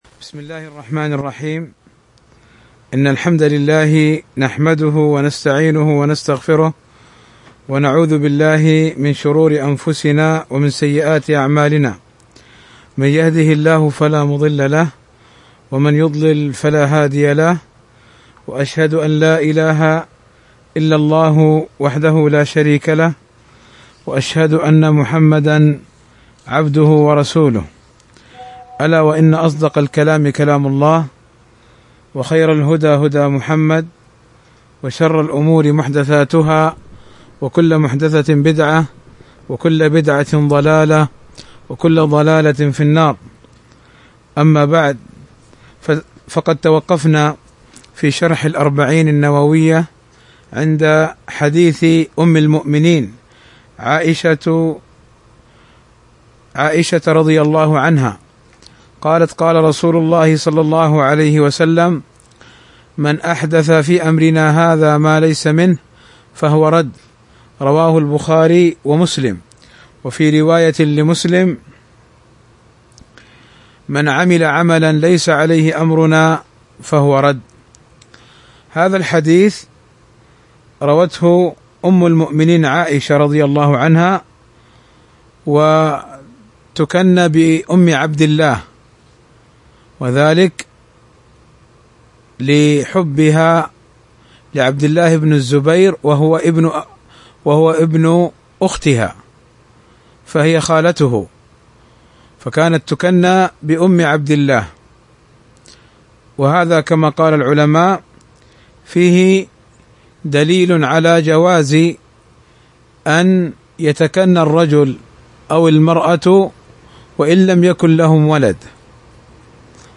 شرح الأربعون النووية الدرس 8